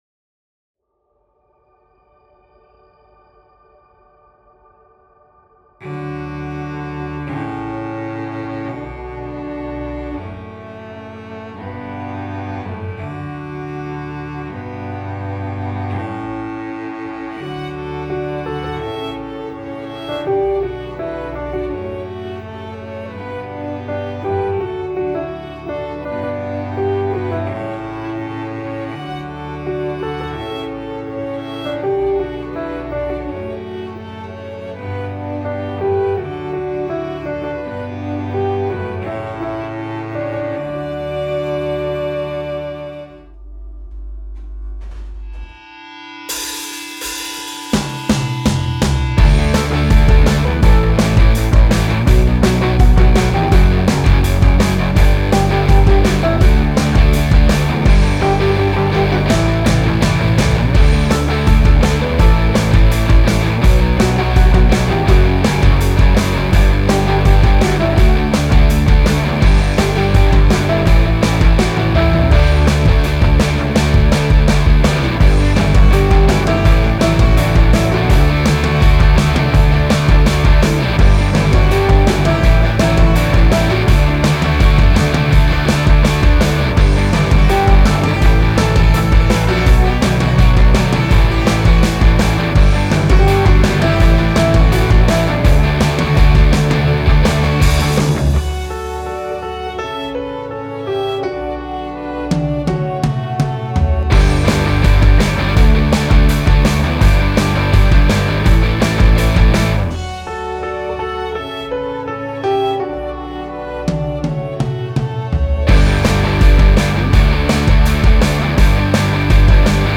Version instrumentale avec guide-chant